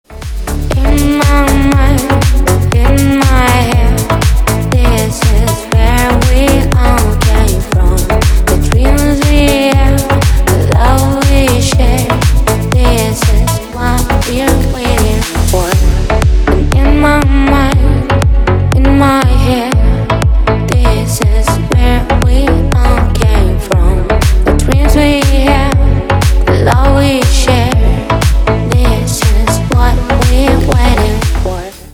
ремикс на входящий